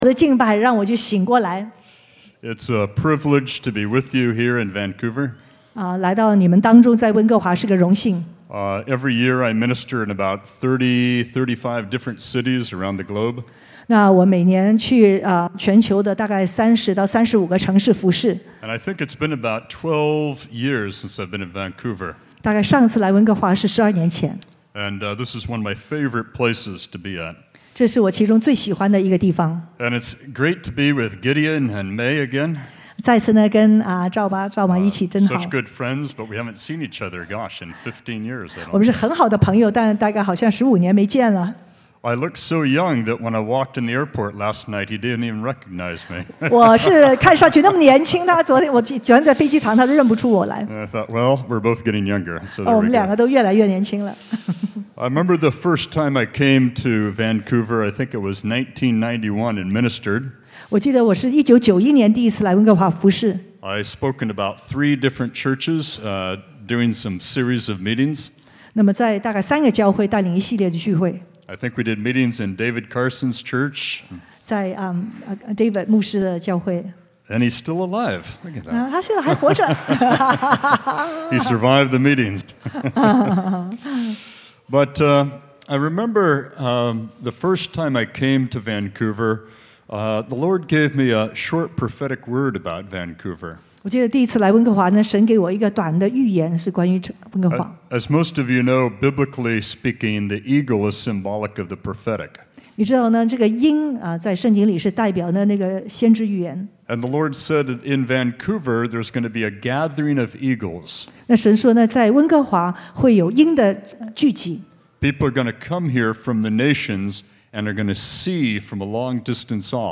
城市复兴特会（1）